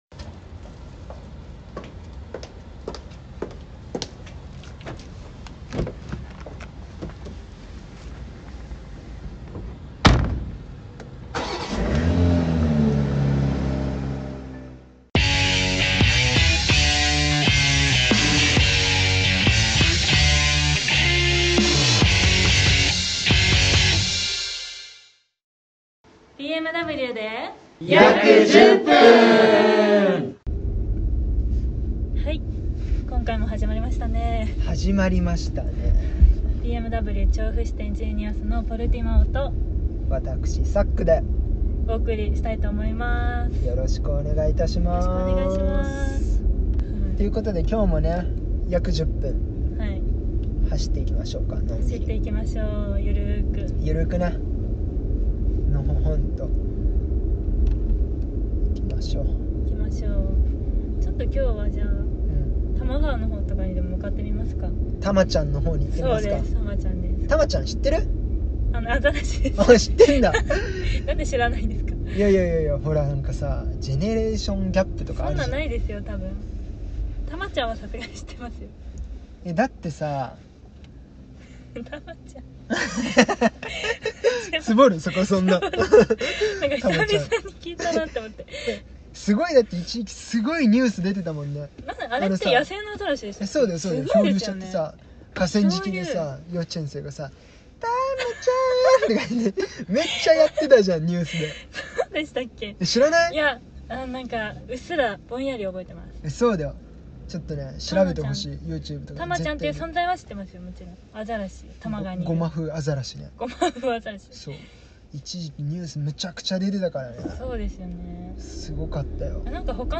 BMWに乗りながら愉快に話すカップルの会話をちょっと聴かせてもらおうというコンテンツです。今回は、多摩川付近を走りながら、鎌倉ナンバー。